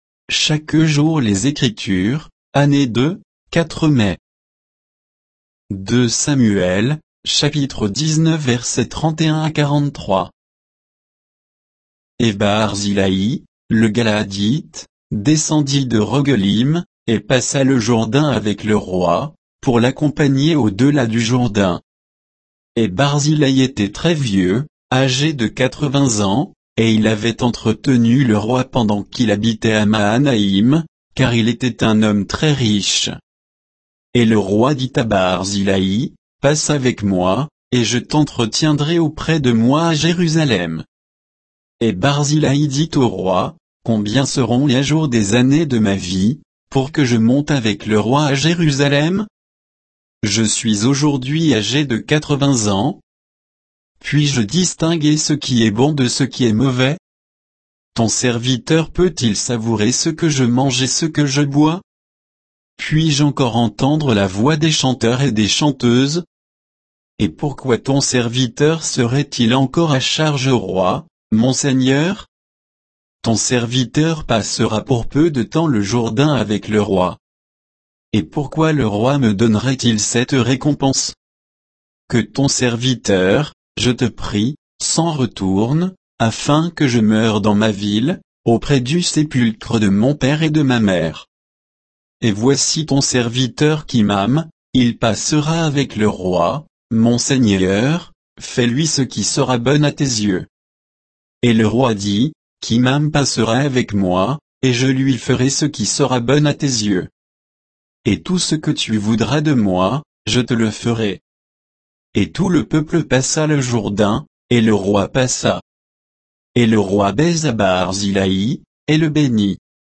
Méditation quoditienne de Chaque jour les Écritures sur 2 Samuel 19